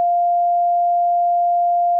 The first of these is a 'Sine Wave'. This is the simplest wave used by FM synthesis, it gives a nice 'smooth' sounding note.
wavSine.wav